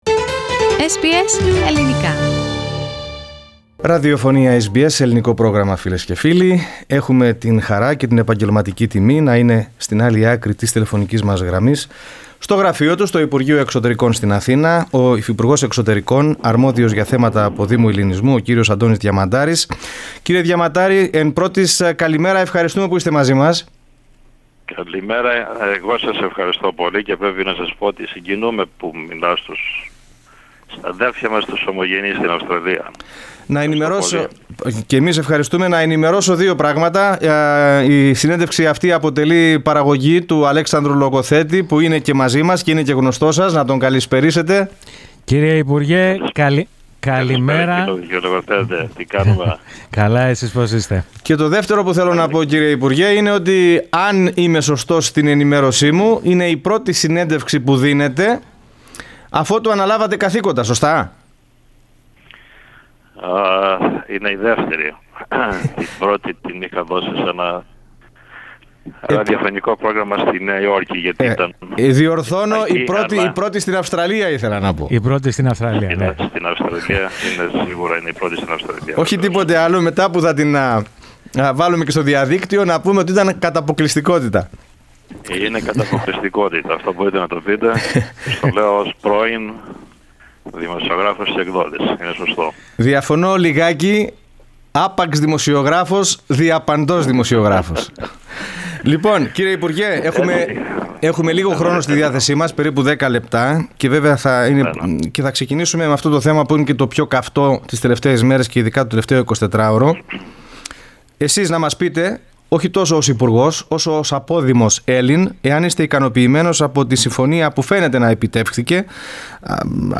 The Deputy Minister of Foreign Affairs in Greece responsible for the Greek Diaspora, Antonis Diamataris speaks to SBS Greek.